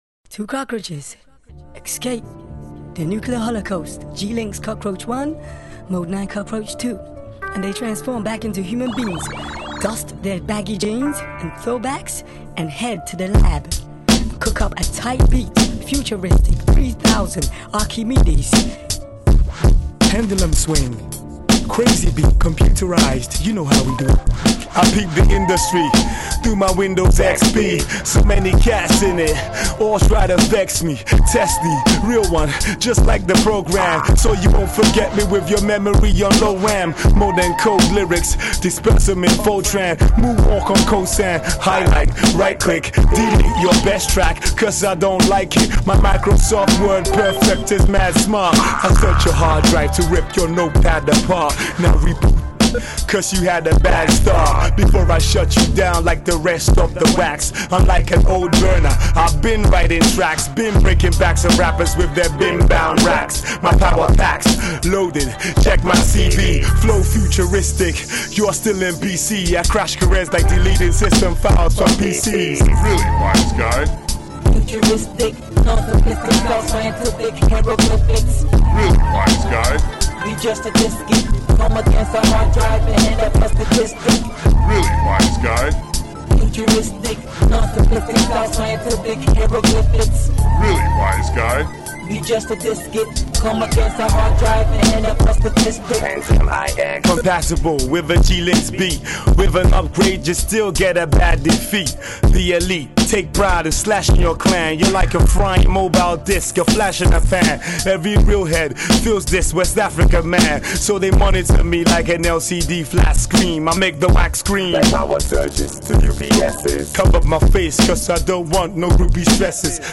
AudioHip-Hop